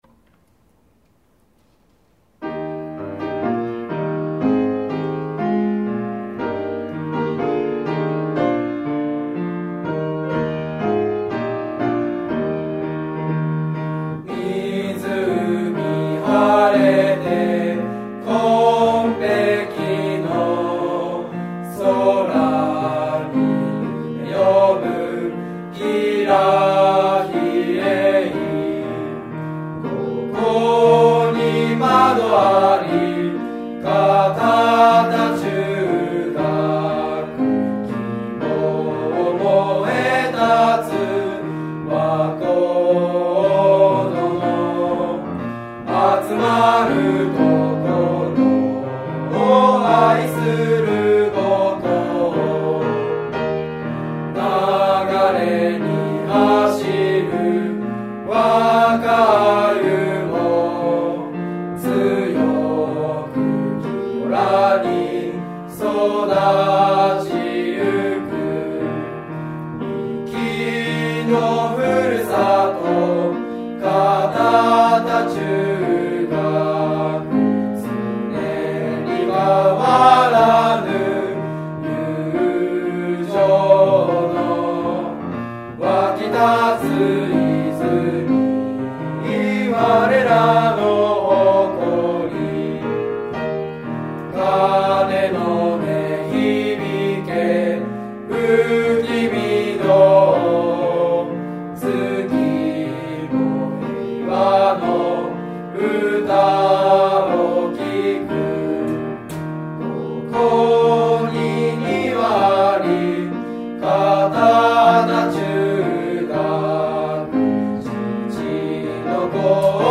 校歌
在校生による校歌の録音データ（mp3形式）
男声